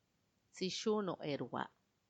be’hatteba[be’atteba]